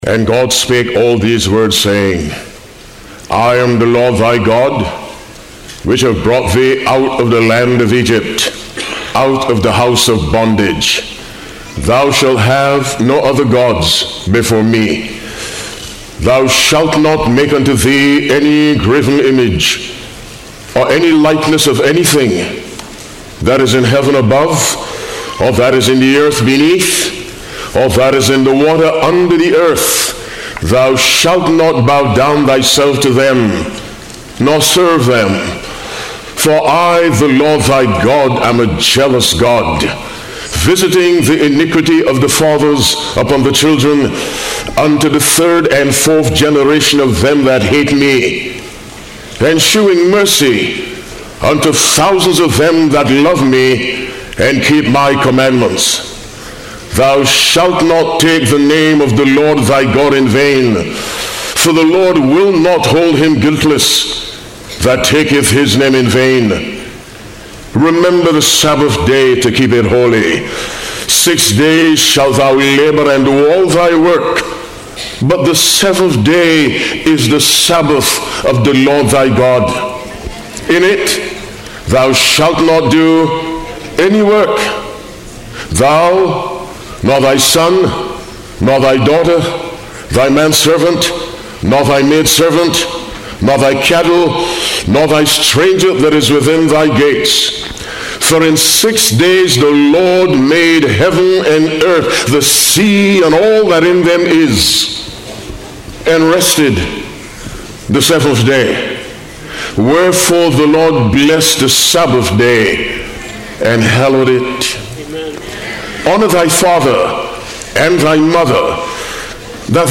Sermons and Talks 2026